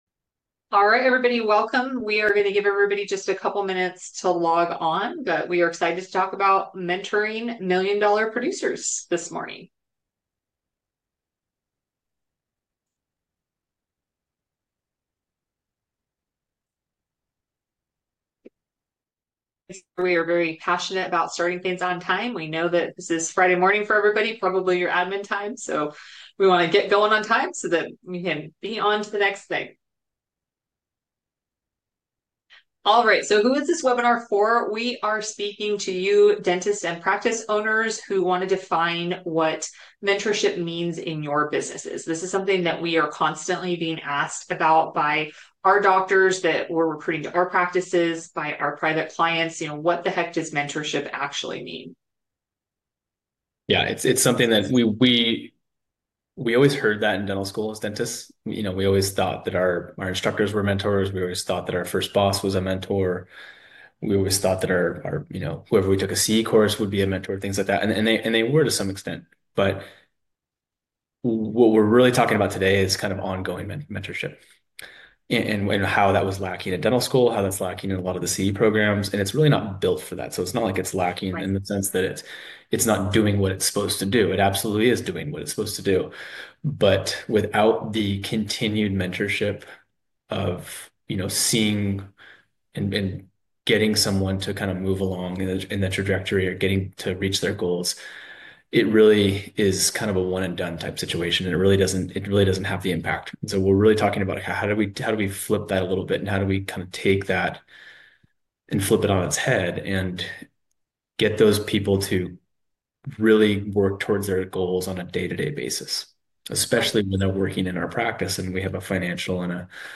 Webinar Replay!  Mentoring Million Dollar Producers: A New Paradigm in Dental Practices Join Pathway Dental Solutions as we explore the transformative power of mentorship for dentists and practice owners aiming to nurture million-dollar producers.